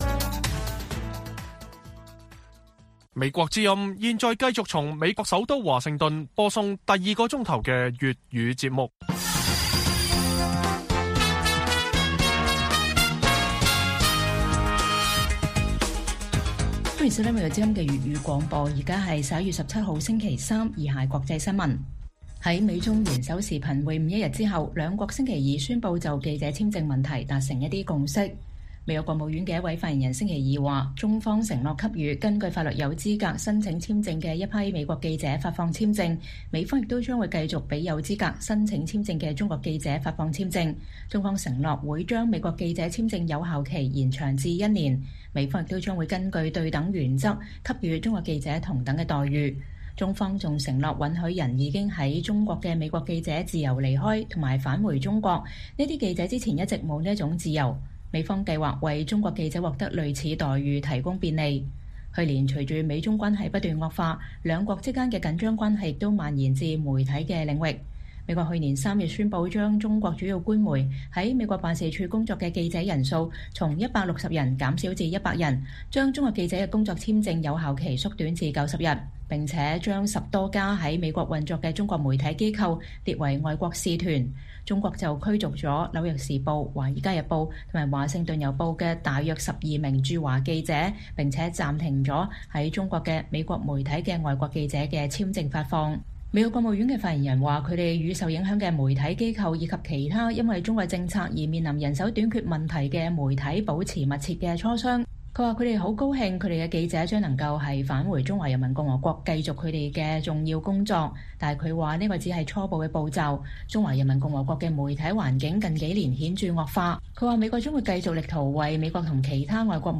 粵語新聞 晚上10-11點: 香港立法會選舉“非建制派”傾巢而出 學者：兩面不是人選情難樂觀